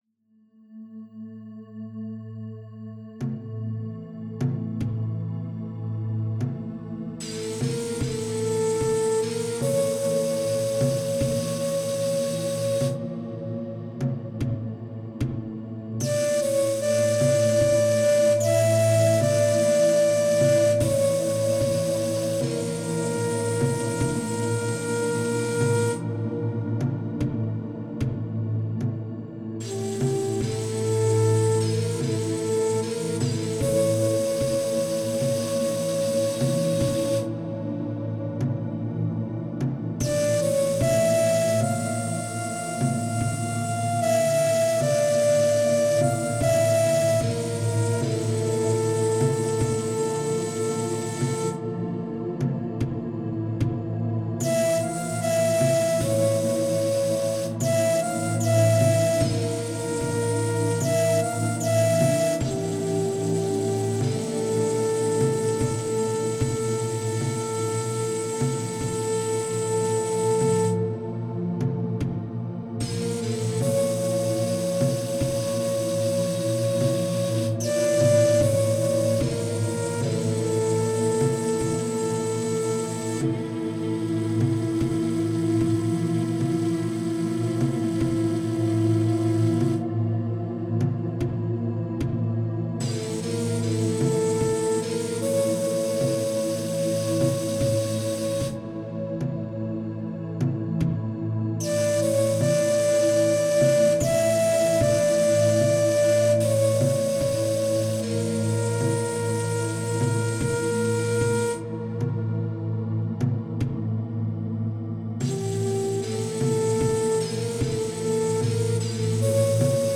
Genre: Ambient.